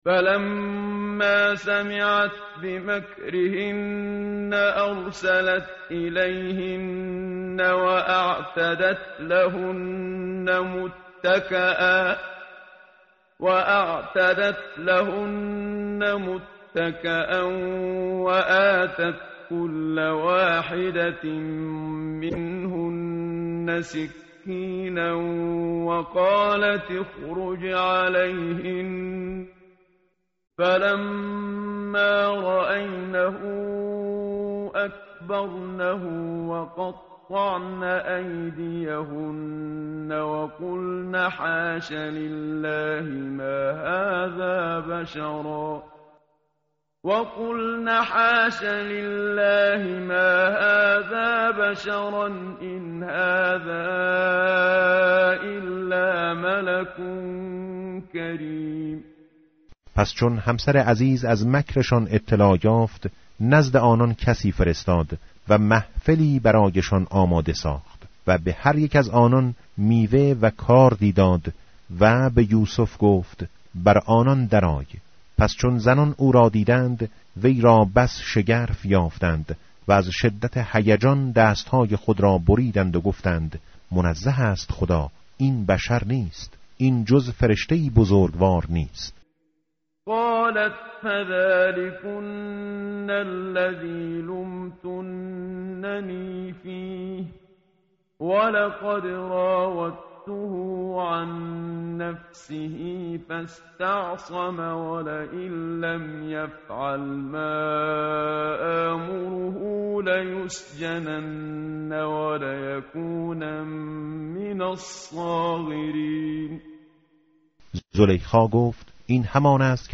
متن قرآن همراه باتلاوت قرآن و ترجمه
tartil_menshavi va tarjome_Page_239.mp3